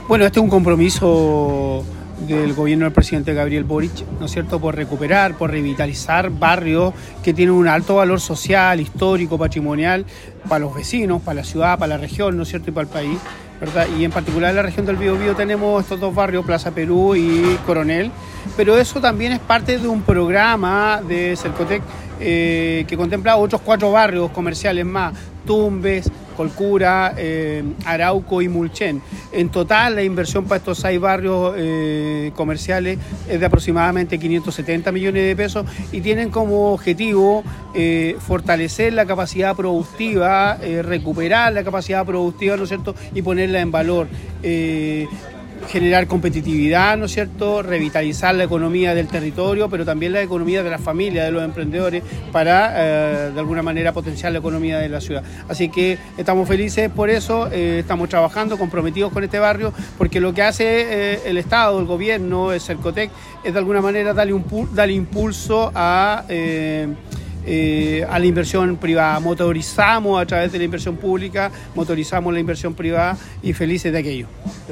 Mientras que el director regional de Sercotec, Mauricio Torres, se refirió a los alcances del programa.